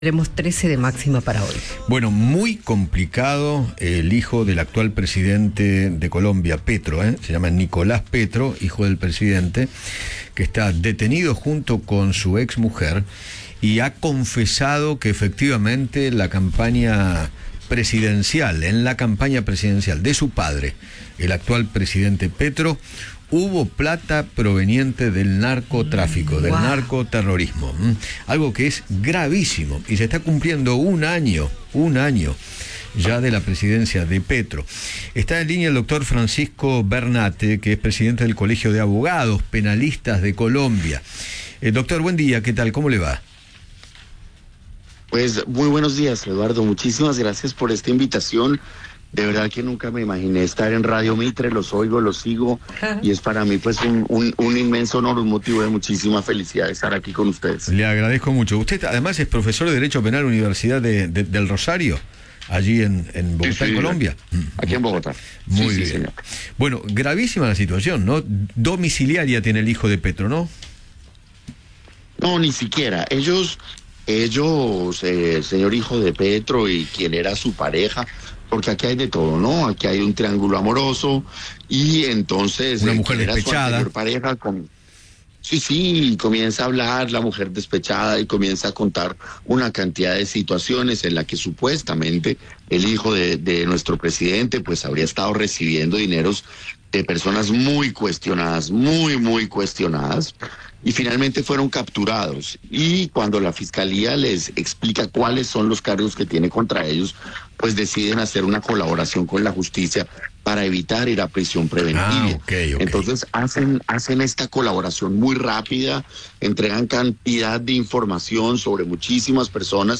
Eduardo Feinmann